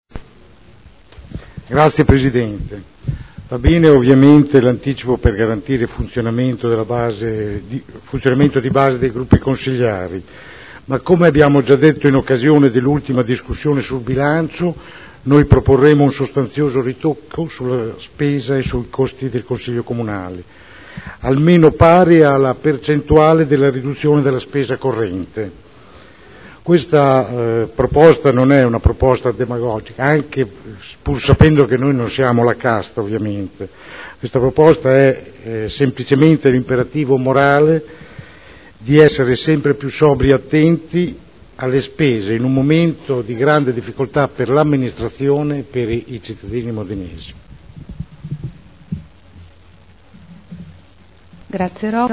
Francesco Rocco — Sito Audio Consiglio Comunale